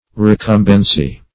recumbency - definition of recumbency - synonyms, pronunciation, spelling from Free Dictionary
Recumbency \Re*cum"ben*cy\ (-ben*s?), n.